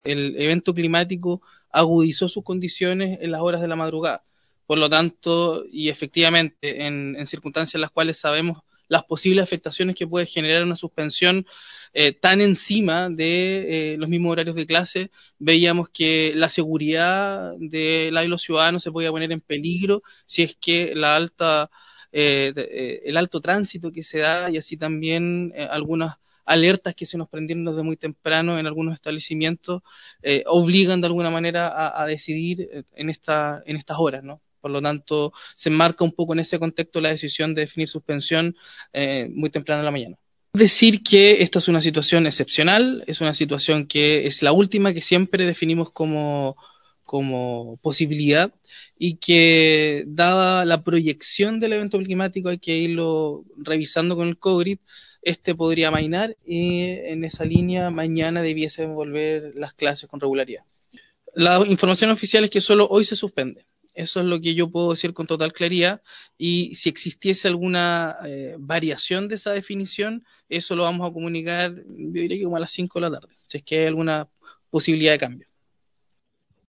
En punto de prensa desarrollado a las 09:30 horas en la Dirección Regional de Senapred, el Seremi de Educación de Los Ríos Juan Pablo Gerter, entregó detalles de la suspensión de clases de este día jueves 08 de junio, y las medidas adoptadas ente esta situación que obligó por la seguridad de los actores de la educación.